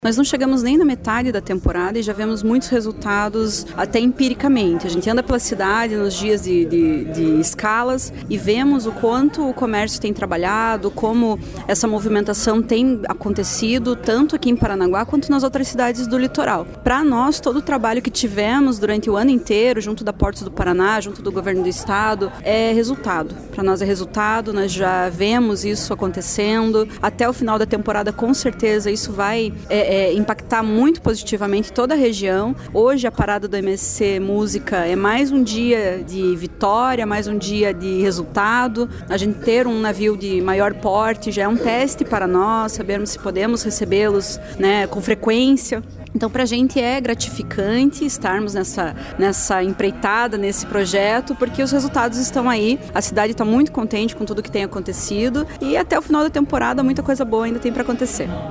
Sonora da secretária de Cultura e Turismo de Paranaguá, Maria Platin, sobre a parada do navio de cruzeiro Musica, da MSC